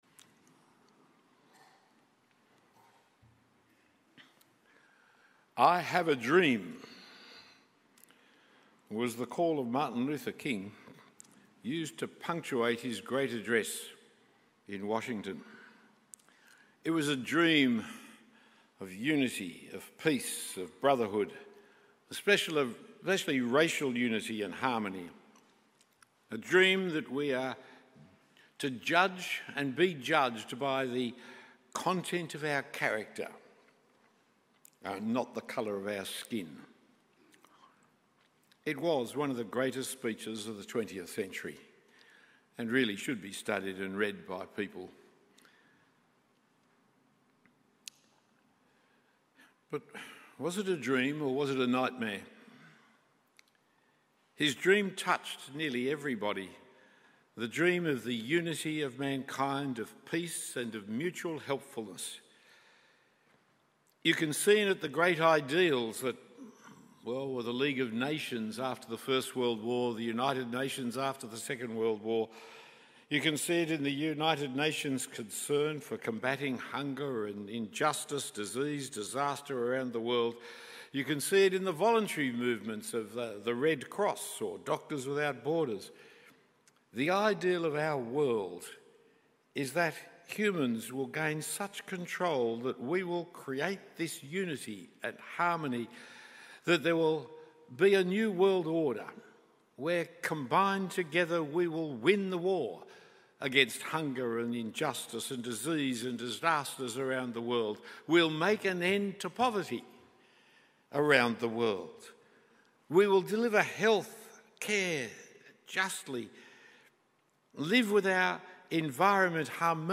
Talk 2 of 2 given at a half-day conference, The Paradox of a Living Death, at St Thomas North Sydney.